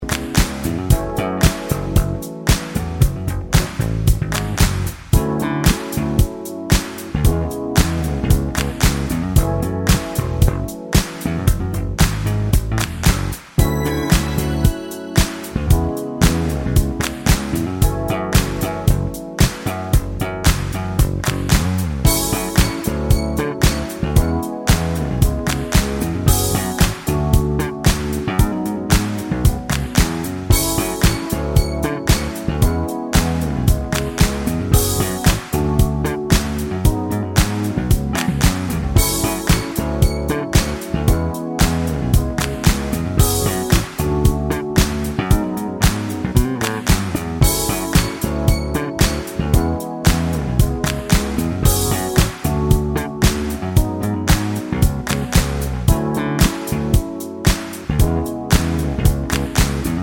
no Backing Vocals R'n'B / Hip Hop 4:12 Buy £1.50